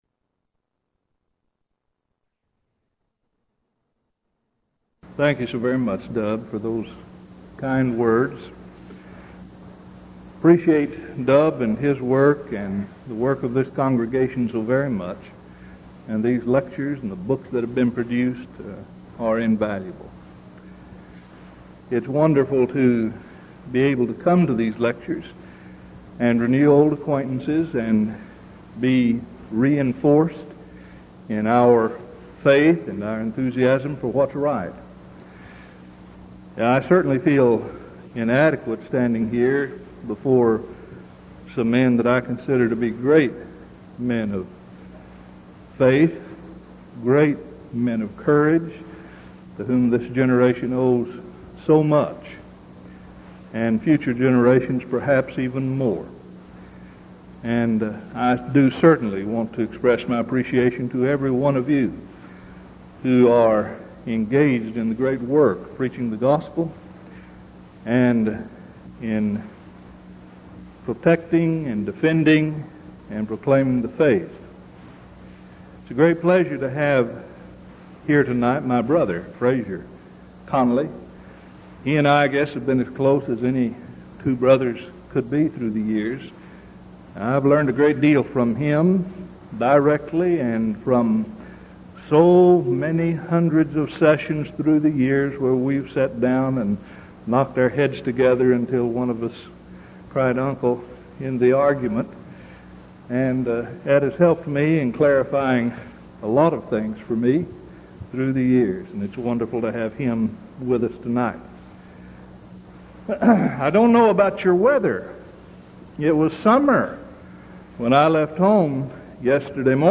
Event: 1986 Denton Lectures Theme/Title: Studies in Galatians
lecture